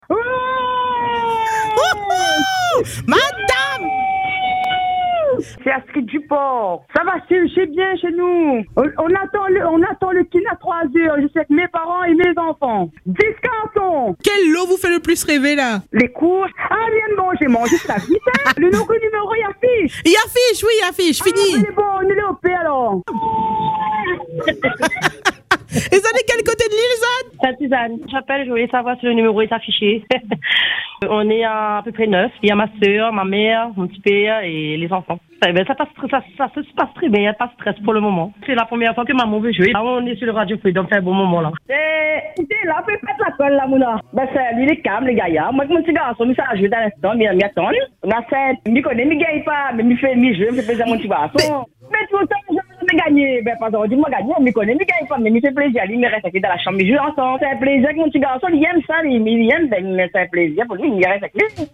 Les heureux gagnants ont été sélectionnés au cours de plusieurs tirages au sort et ont remporté des lots incroyables dans une ambiance déchaînée :